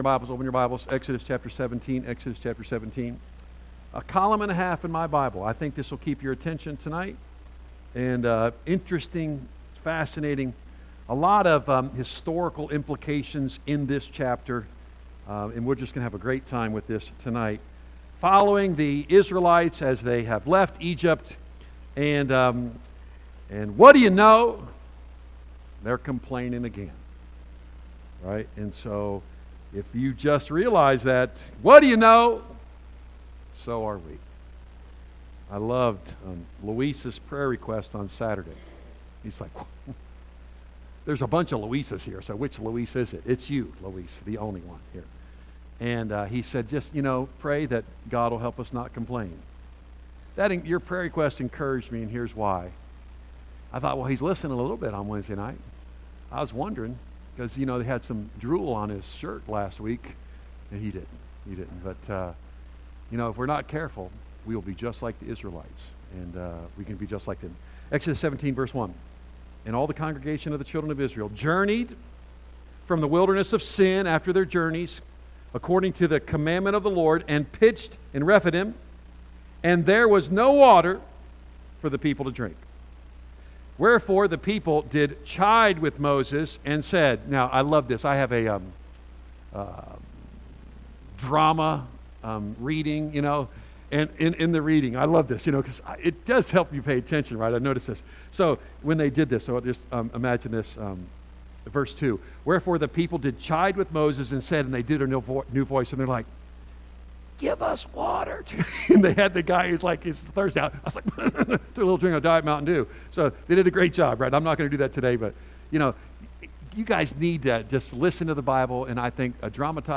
A message from the series "Exodus."